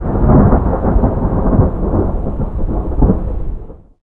thunder13.ogg